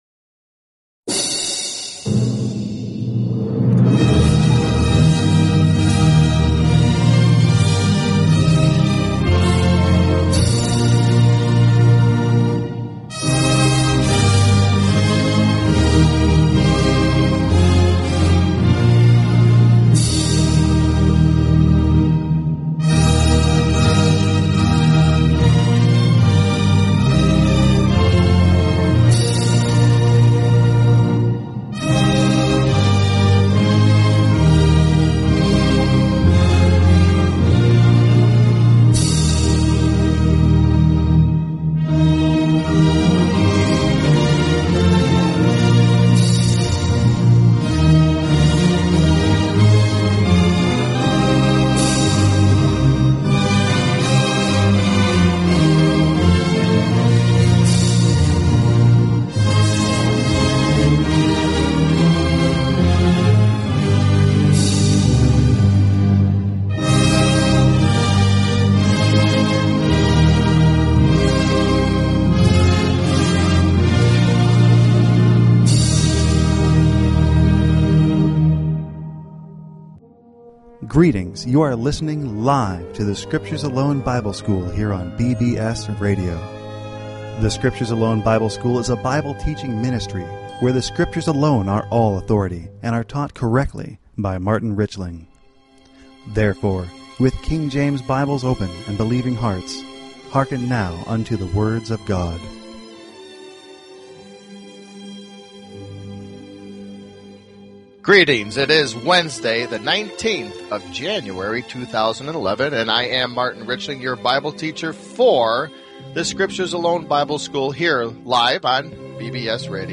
Talk Show Episode, Audio Podcast, The_Scriptures_Alone_Bible_School and Courtesy of BBS Radio on , show guests , about , categorized as